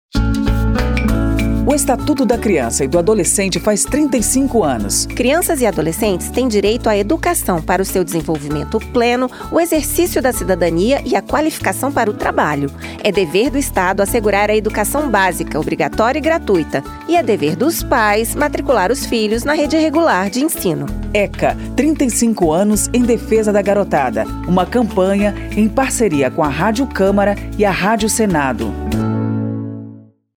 10-spot-eca-35-anos-parceiras.mp3